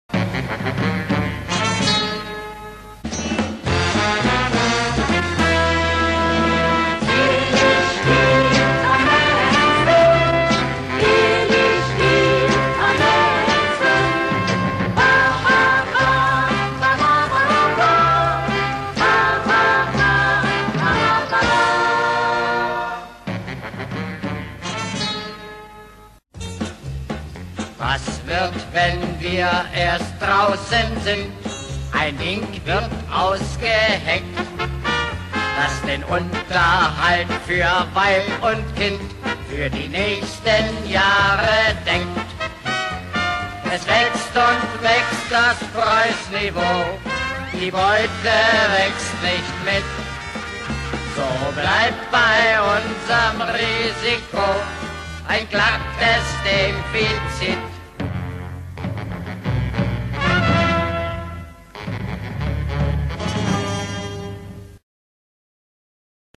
Musical für den Rundfunk (Originalhörspiel)
Besetzung: 9 Darsteller (Sprecher/Sänger)